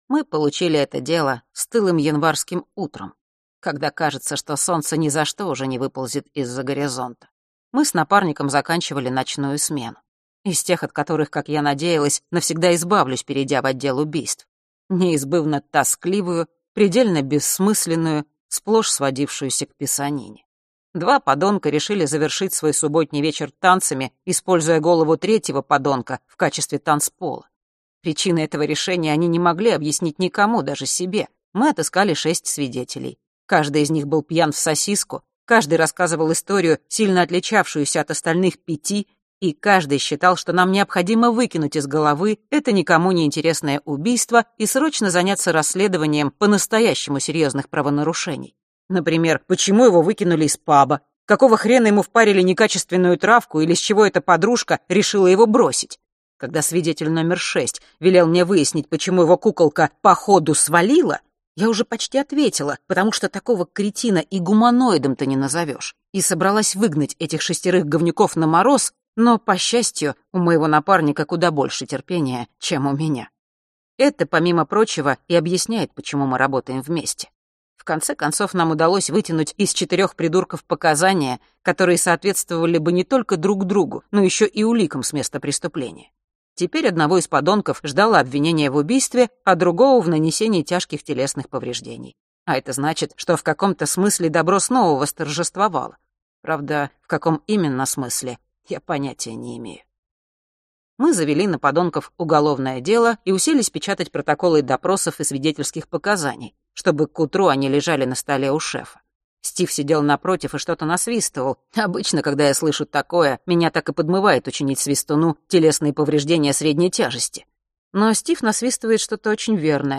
Аудиокнига Тень за спиной | Библиотека аудиокниг